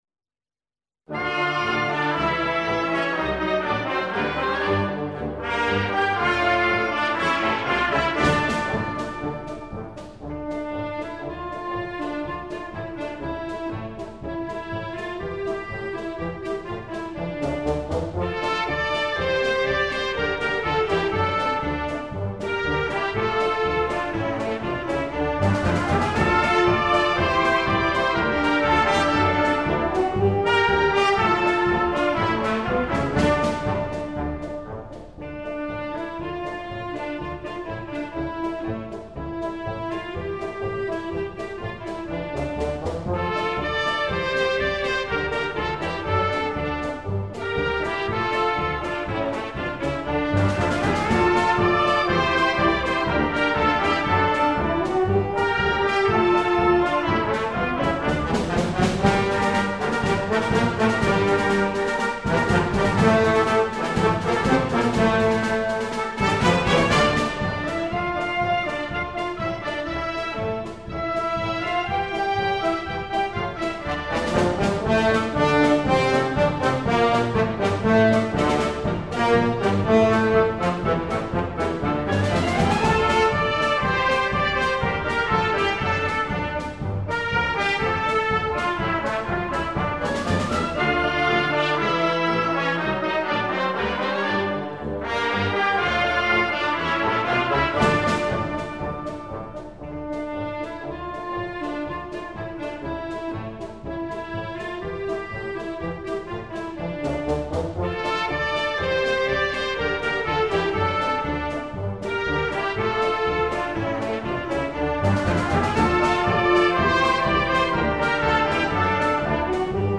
全数码最新录制、音响效果至臻完美，实为爱好者珍贵之收藏。